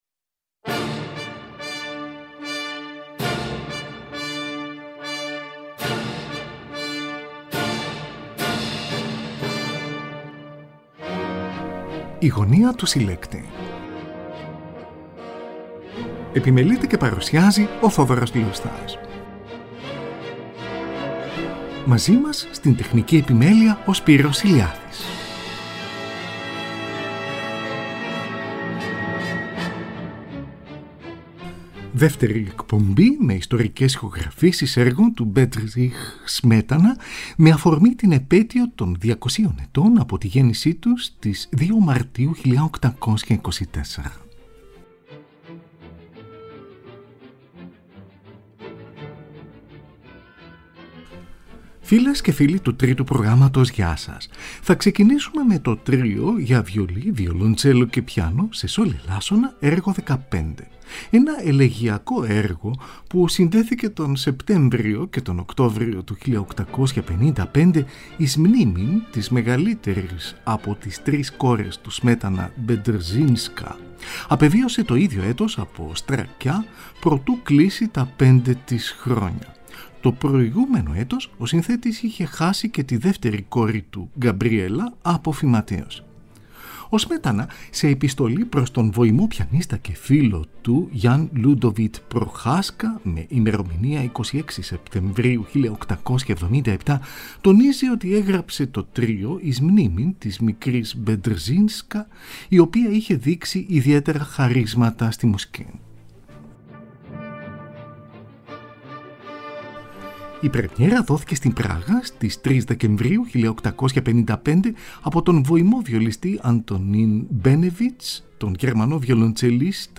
Τρίο για βιολί, βιολοντσέλο και πιάνο, έργο 15.
από ηχογράφηση σε studio
Καντάτα για χορωδία και ορχήστρα
Ορχηστρική εισαγωγή